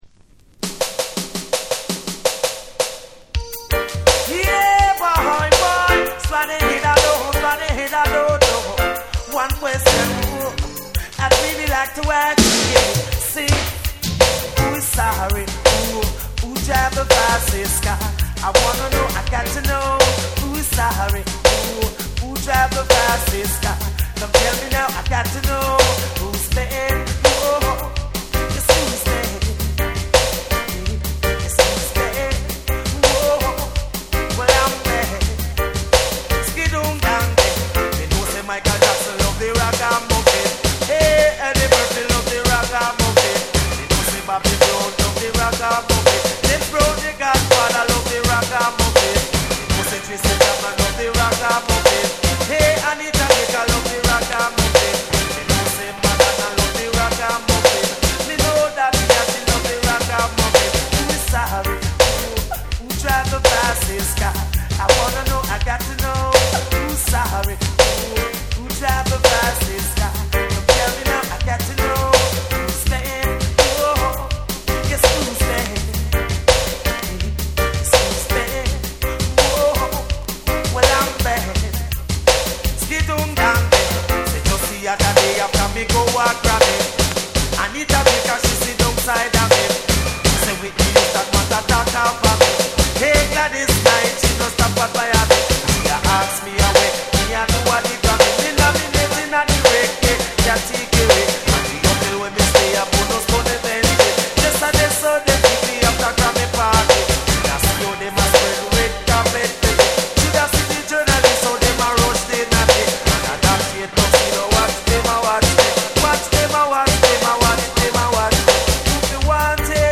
REGGAE & DUB